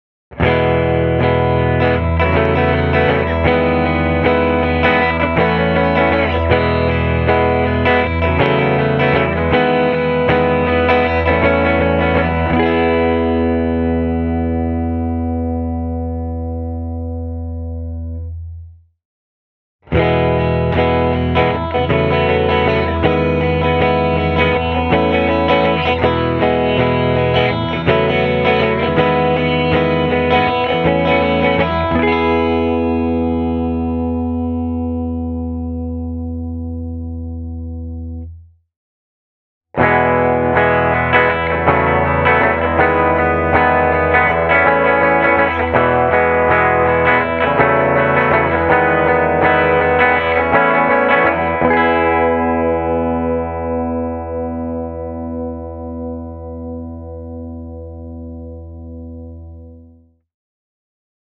Played with a clean amp the Tokai’s neck pickup gives you warm and full tones with a nicely rounded top end. As is the case with most two-humbucker guitars, putting the AJG-88’s toggle in the centre position will result in the most useable clean rhythm tones. The sound is open and airy, with a nice helping of plectrum attack.